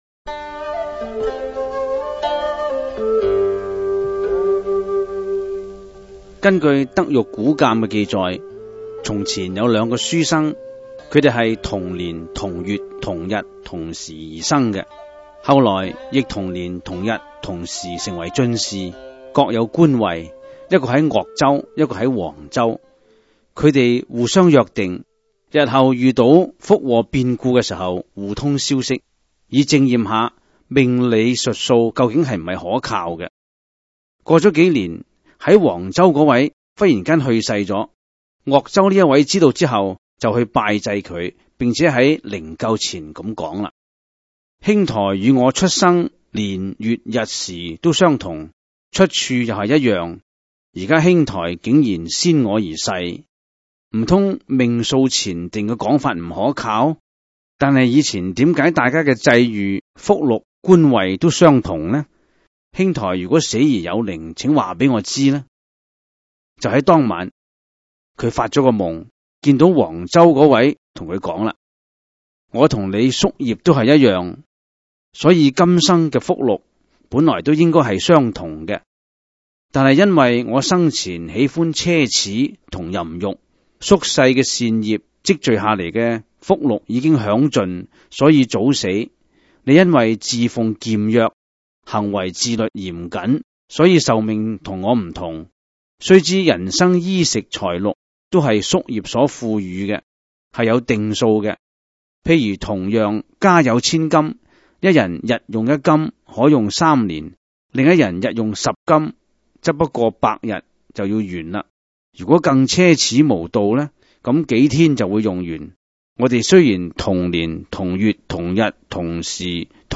第 二 十 辑    (粤语主讲  MP3 格式)